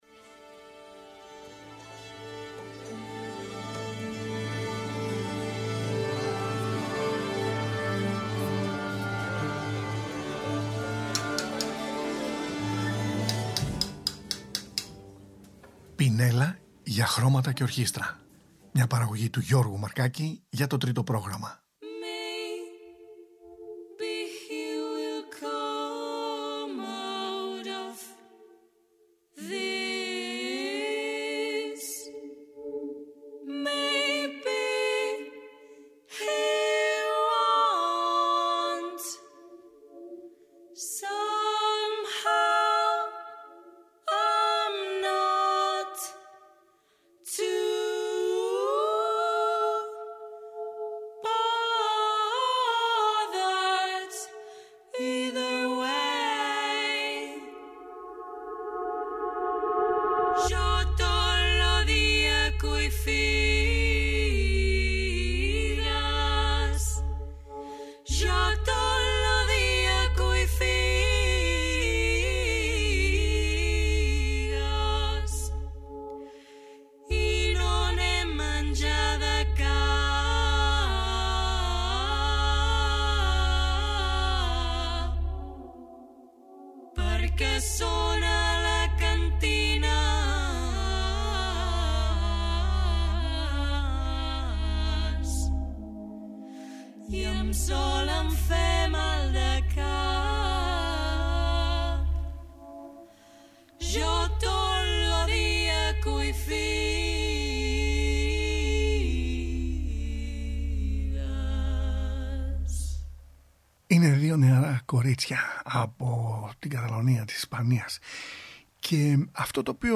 Καταλανικό τραγουδιστικό δίδυμο a capella
πολυφωνίες από την Κορσική, σεφαραδίτικες μελωδίες
ρεμπέτικους ρυθμούς